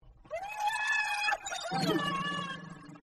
King Pig Crying Sound Effect Download: Instant Soundboard Button